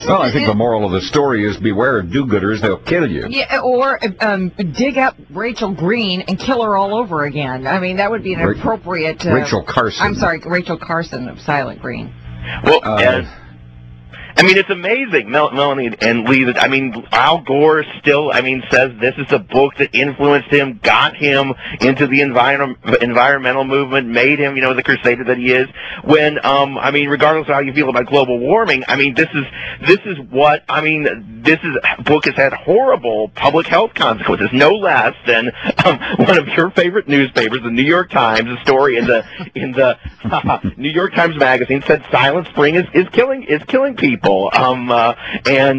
broadcast on the 6:00 hour on December 01, 2006